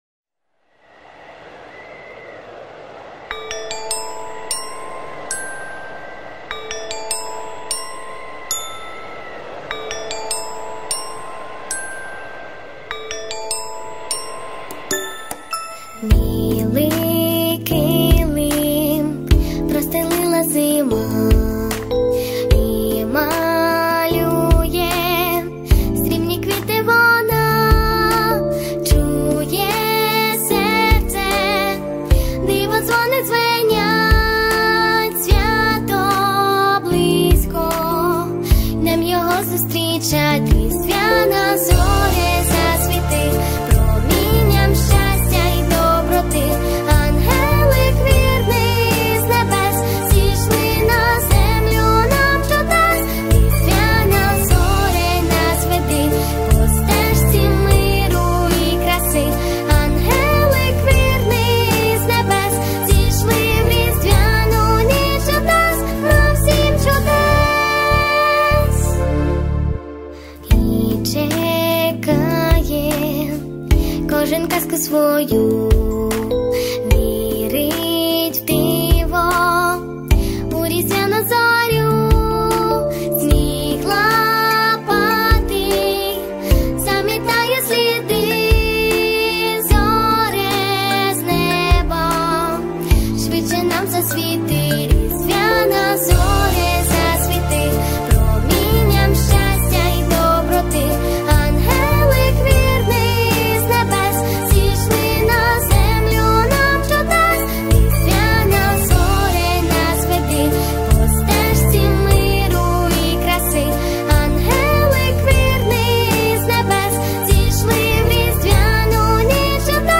Плюсовий запис